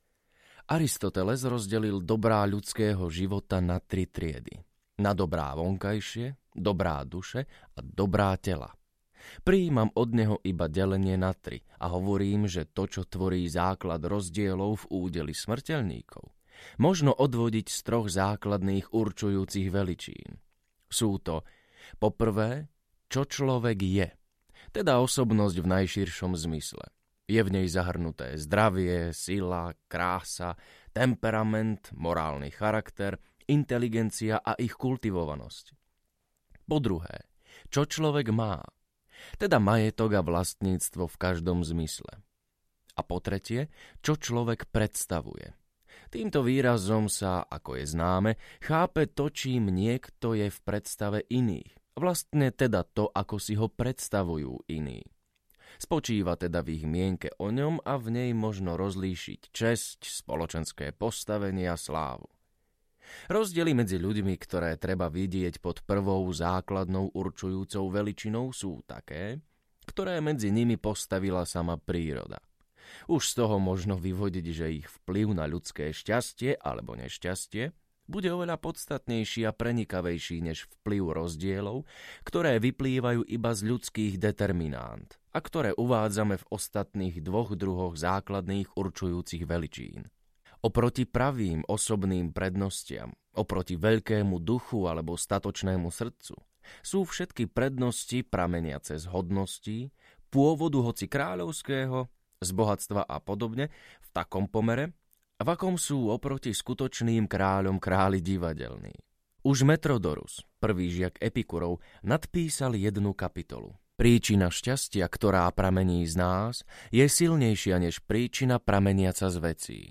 O životnej múdrosti audiokniha
Ukázka z knihy